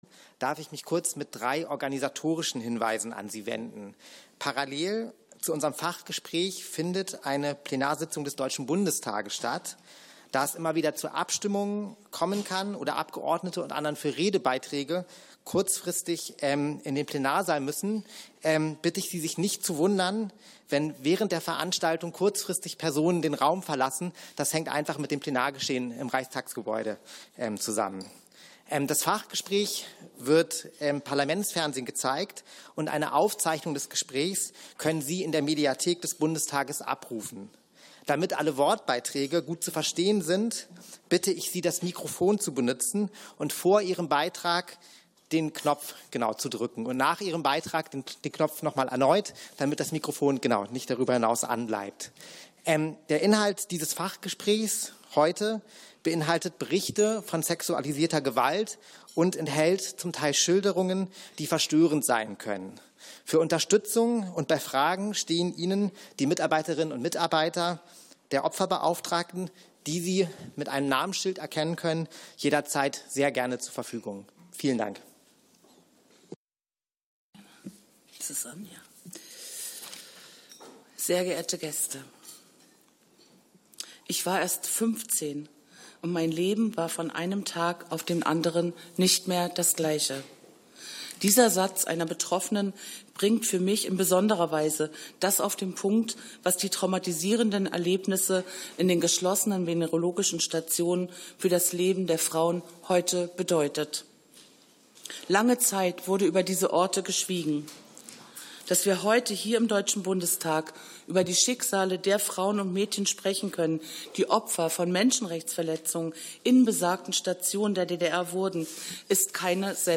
Fachgespräch der SED-Opferbeauftragten